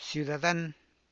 runy uas[ruhny ua’s]